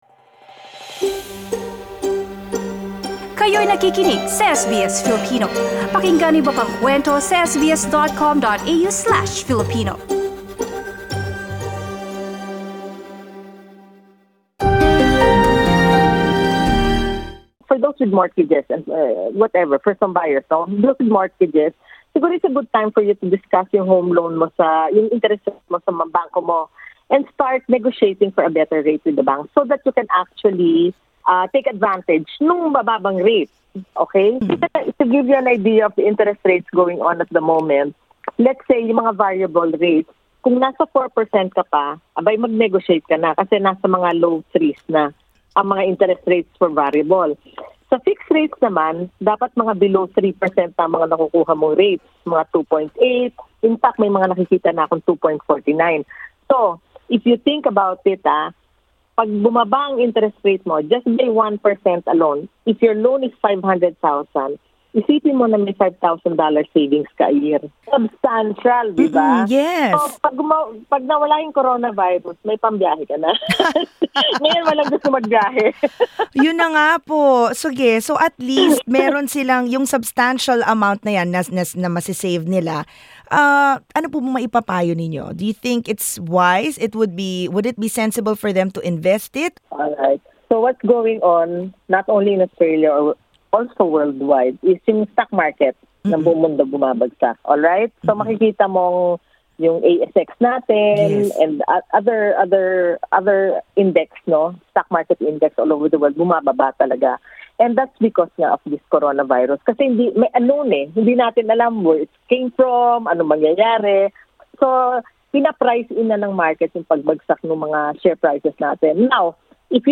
Mainam rin daw na suriin kung saan pwedeng ilagak ang pera para masulit ang savings mula sa mas mababang interes. Pakinggan ang panayam.